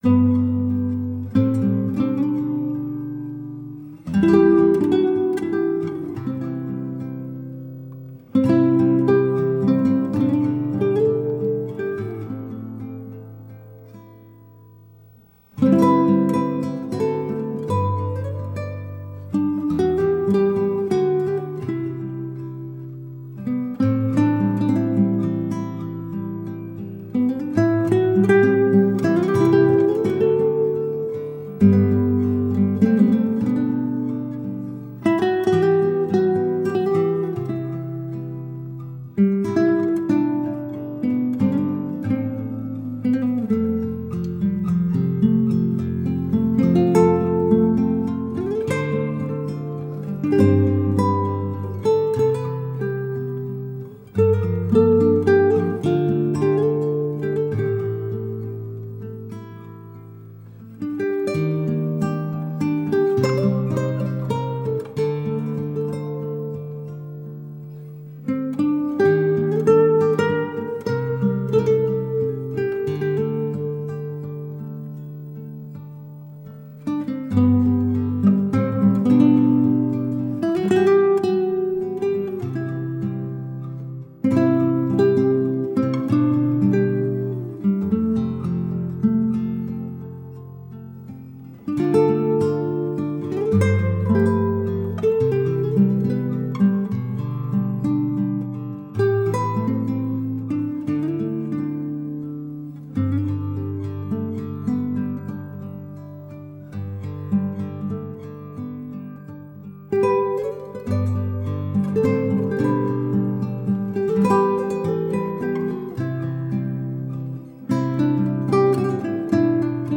موسیقی بی کلام آرامشبخش با گیتار ( با آرزوی آرامش عمیق و همیشگی برای همه عزیزان )